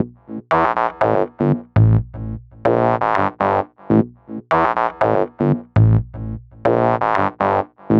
TSNRG2 Bassline 015.wav